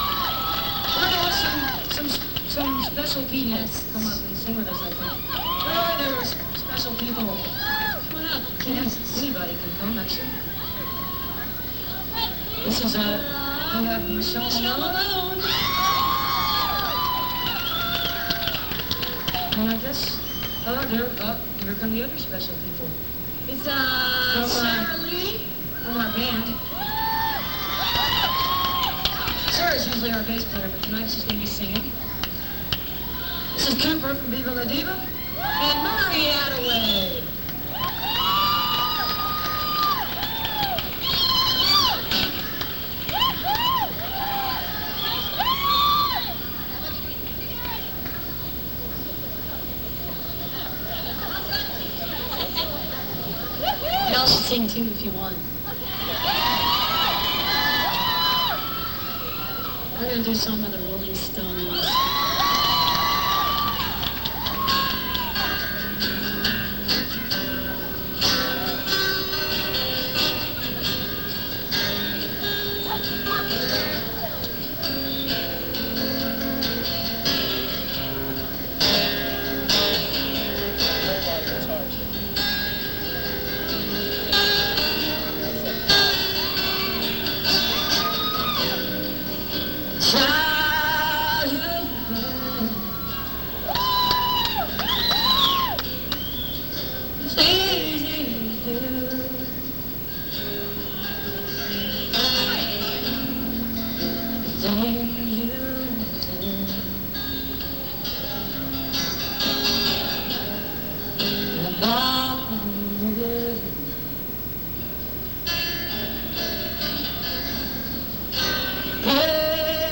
(with group)